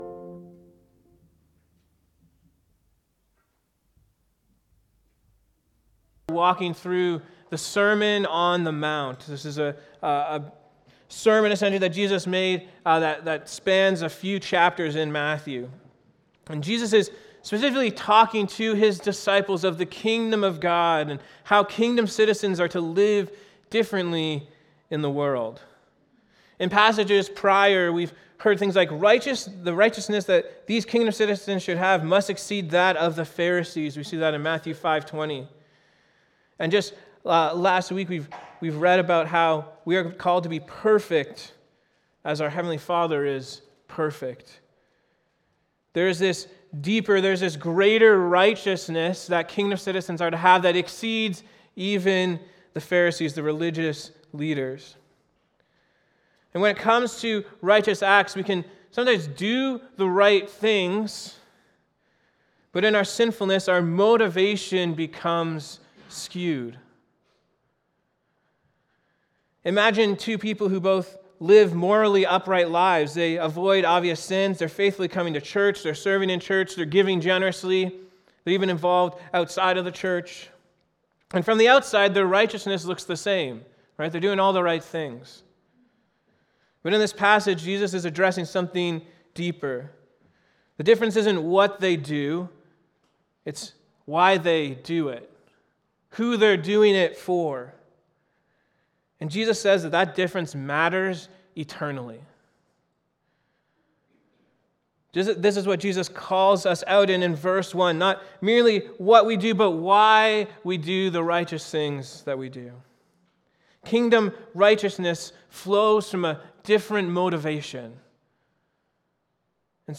A Kingdom Motivation | Matthew 6:1–18 Sermon